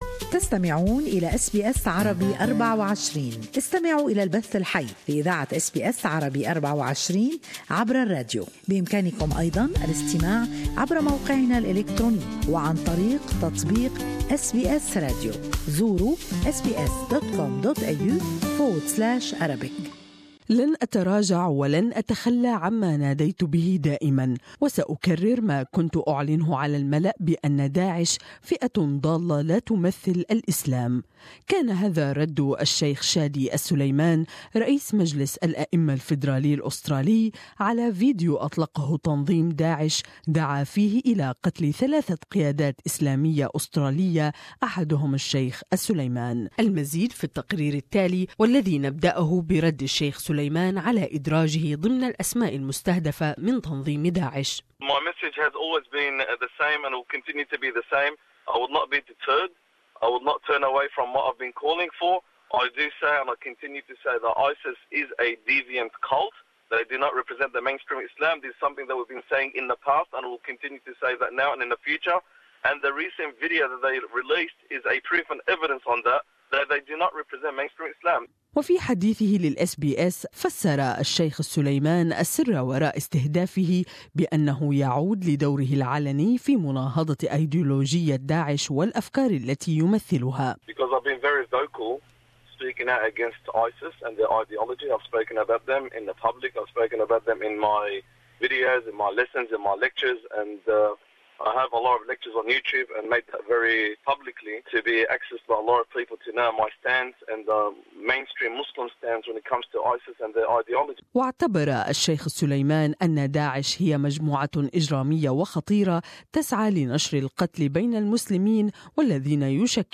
A western Sydney cleric has promised to continue speaking out against the self-proclaimed Islamic State despite the group calling for his murder in a newly released video. More in this report